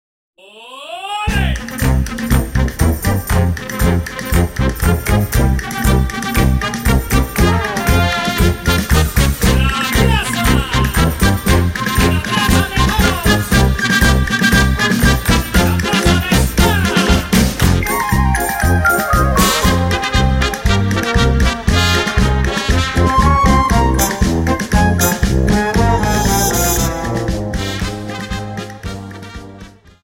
Dance: Paso Doble Song